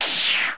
water_shot.mp3